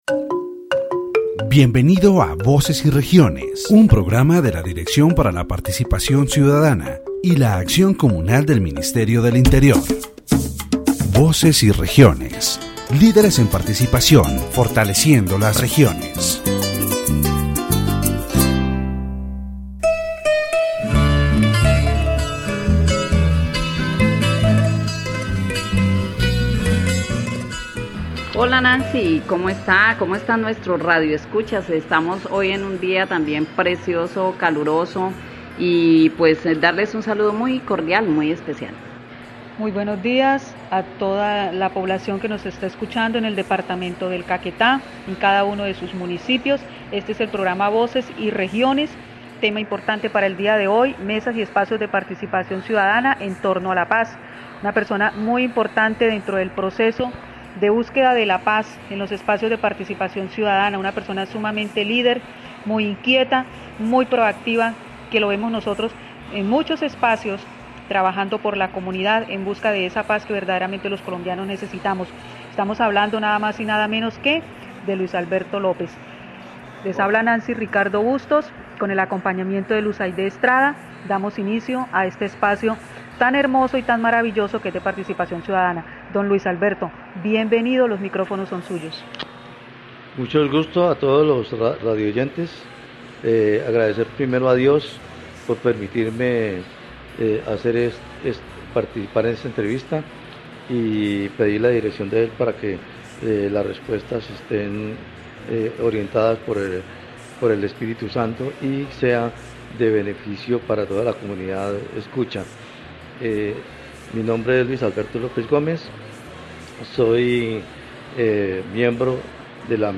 The radio program "Voices and Regions" of the Directorate for Citizen Participation and Communal Action of the Ministry of the Interior focuses on the tables and spaces for citizen participation around peace in the department of Caquetá.